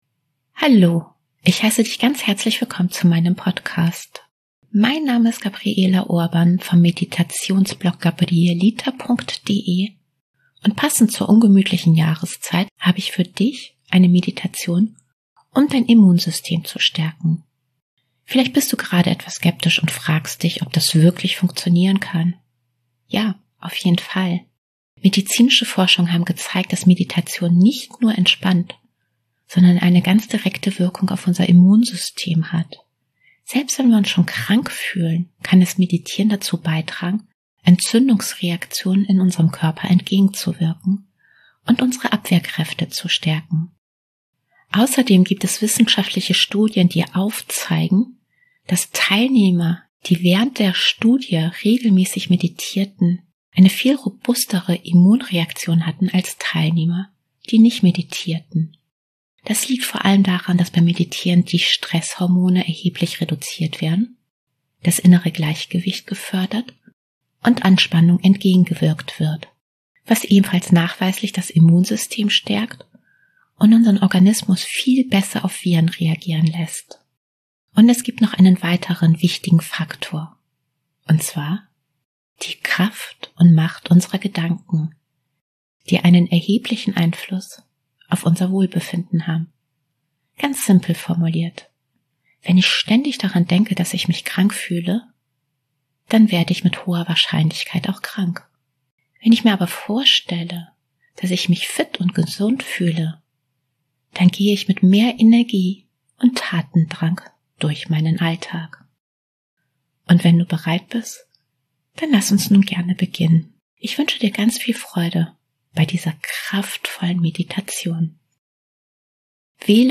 #073: Meditation Immunsystem stärken – fühle dich kraftvoll und vital